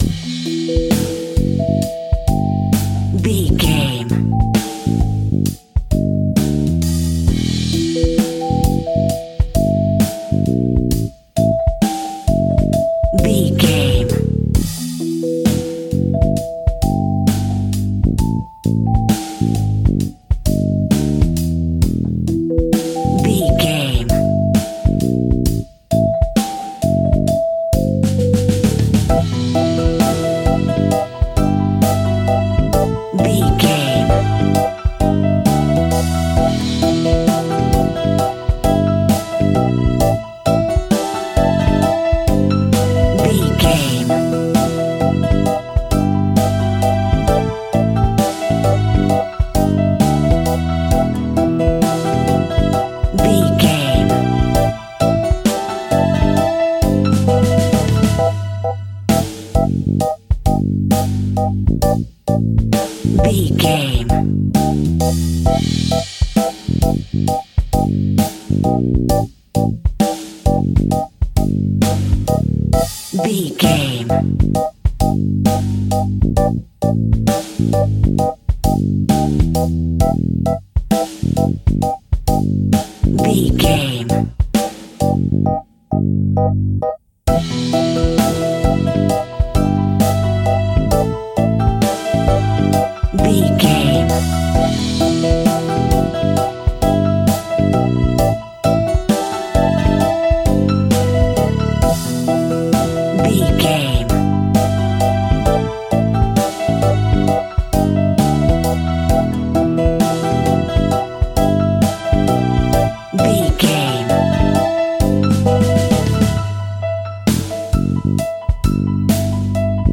Hip Hop Party Chilling.
Aeolian/Minor
Slow
hip hop music
electric drums
drum machine
Hip Hop Synth Lead
Hip Hop Synth Bass
synths